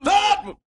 BWB 5 Chant J Cole (4).wav